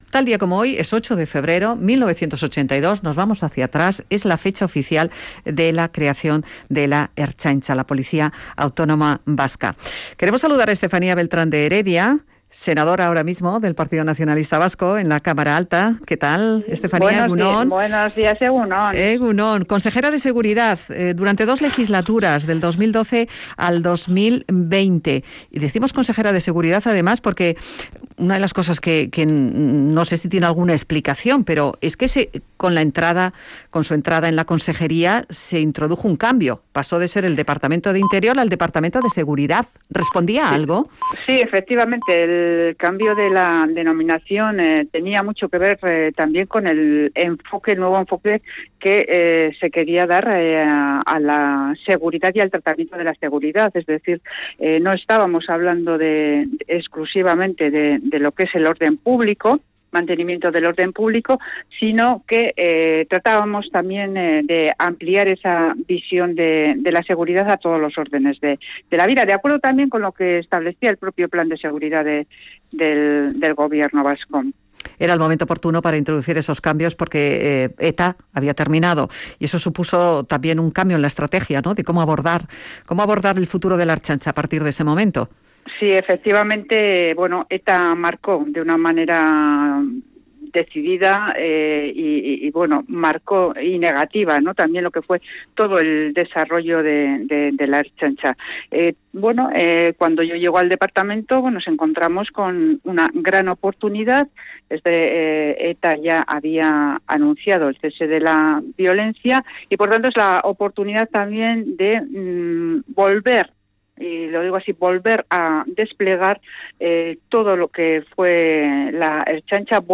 Hoy se cumplen 40 años de la creación de la Ertzaintza. En Radio Vitoria Gaur hemos hablado con Estefanía Beltrán de Heredia, Consejera de Seguridad de 2012 a 2020